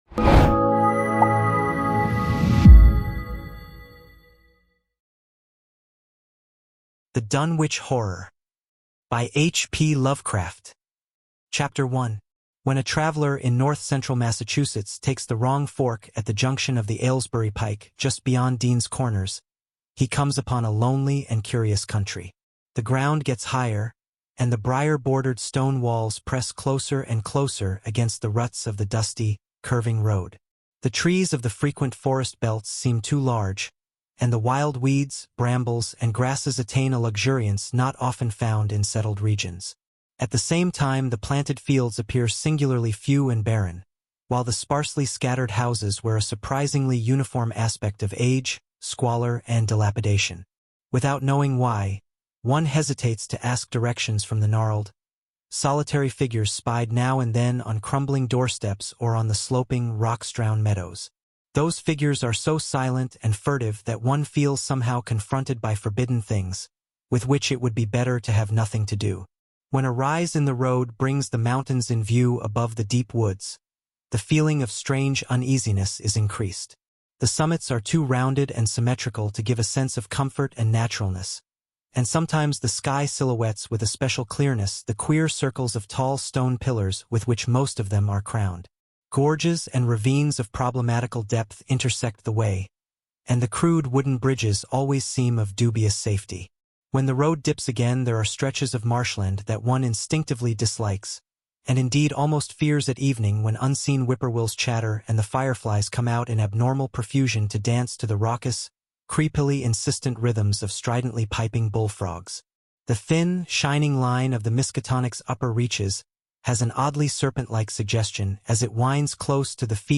Libros Narrados